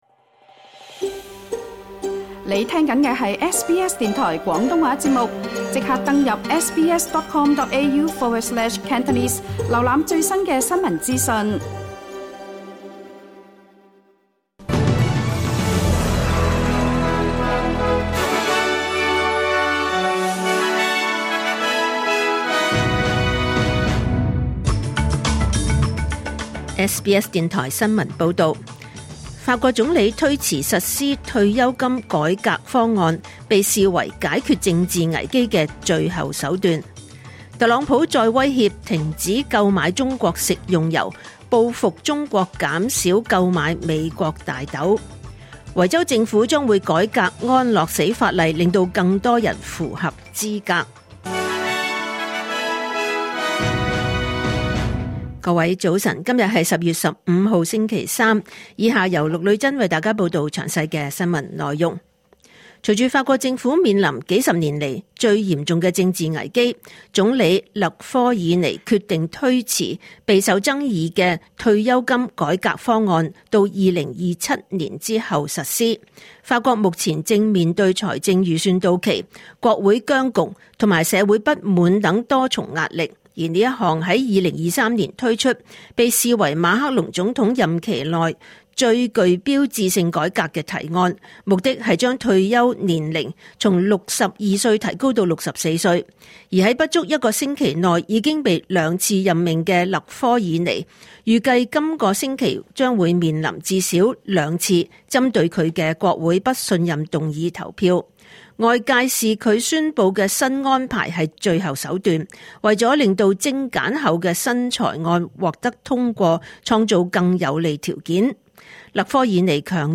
2025年10月15日SBS廣東話節目九點半新聞報道。